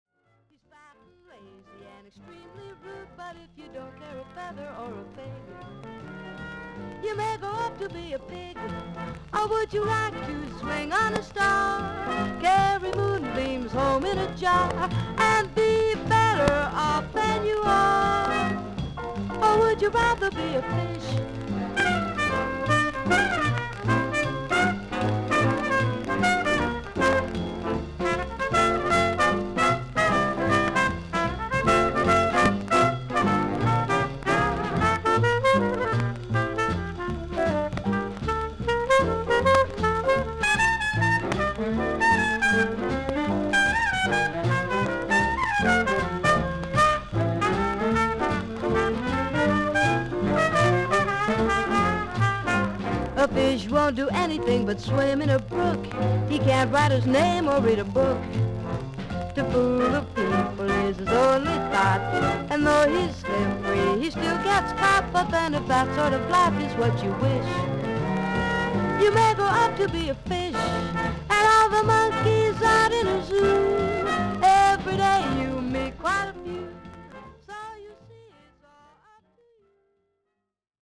A4中盤に5mmのキズ、大きめの周回ノイあり。
所々にパチノイズの箇所あり。全体に少々サーフィス・ノイズあり。音自体はクリアです。
女性ジャズ・シンガー。クールで愛らしい希少なクリア・ヴォイス。